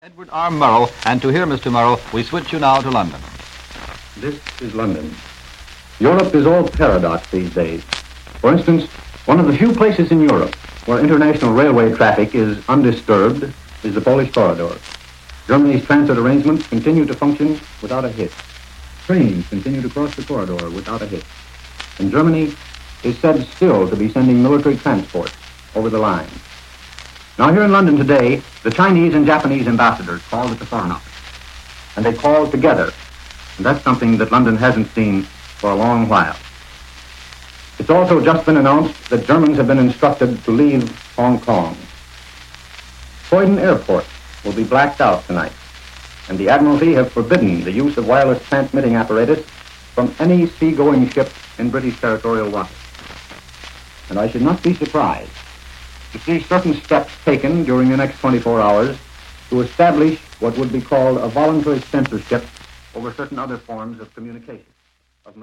The voice of Edward R Murrow, also listen to his voice